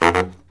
LOHITSAX08-L.wav